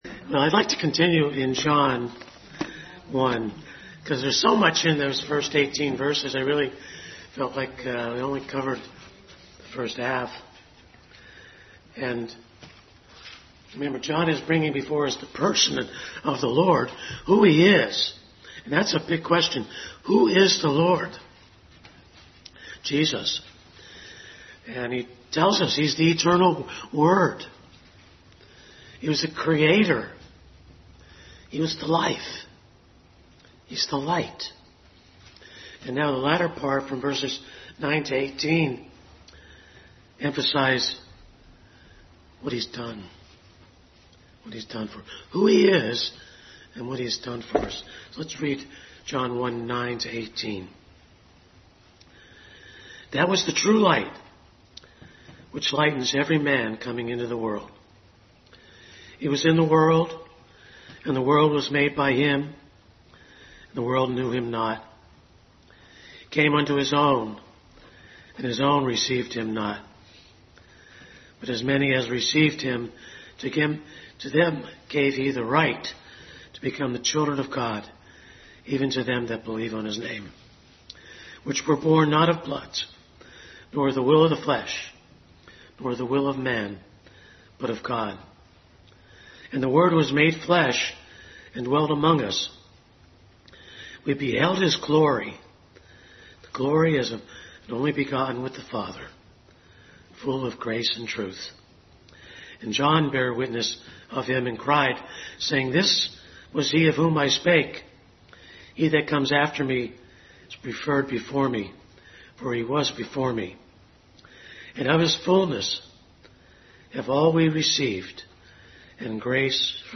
Bible Text: John 1:9-18 | Family Bible Hour Message.